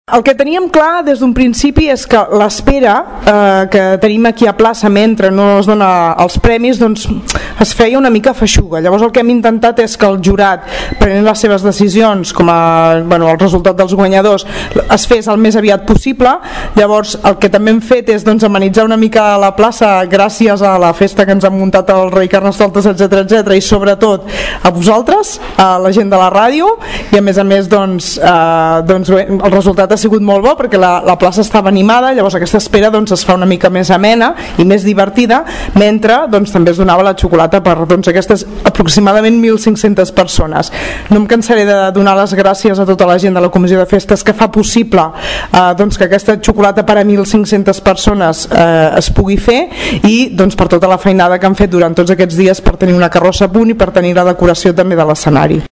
Al final de la jornada, la regidora de festes, Sílvia Català, es mostrava molt contenta amb la participació al Carnestoltes d’enguany.